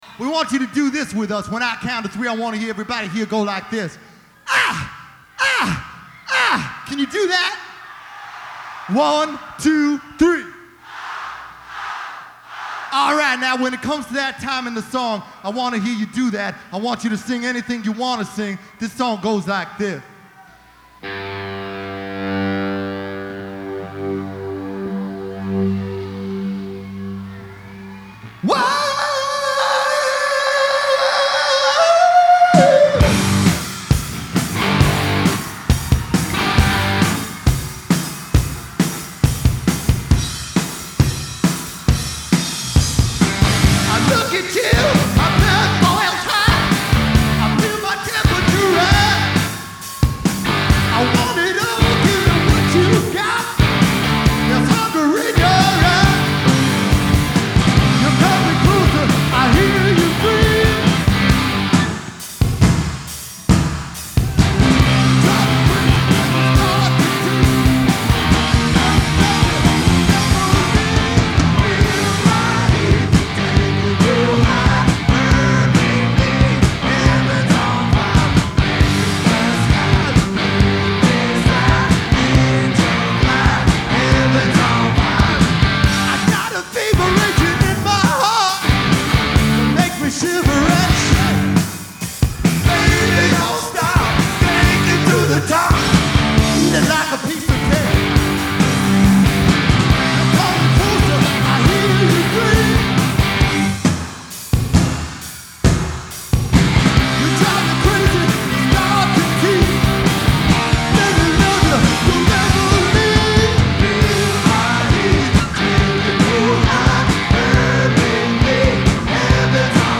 Genre : Rock
Live From Mid-Hudson Civic Arena, Poughkeepsie NY